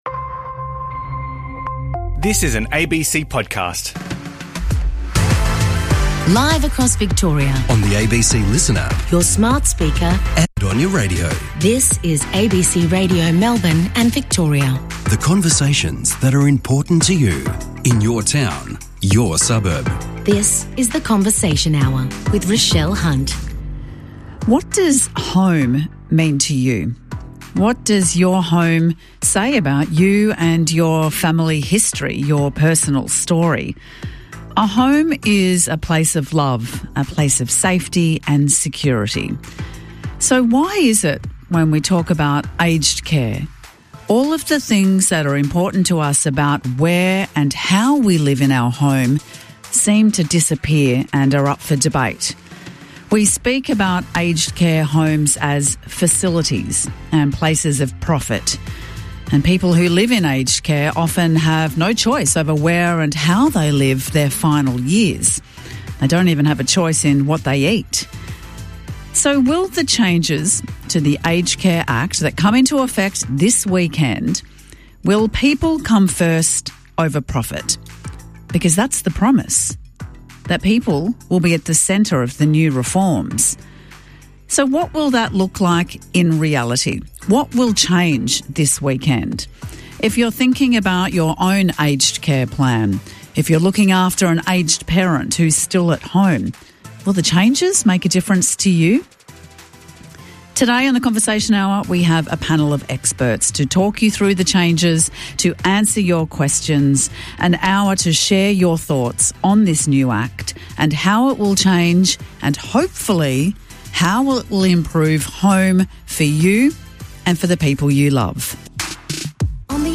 Expect engaging discussions with real people and inspiring stories connecting communities across Victoria